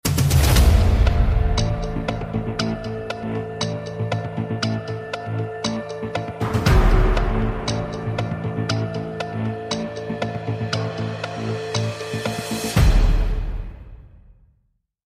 Plane Crash Incident 🛫🔥 Plane sound effects free download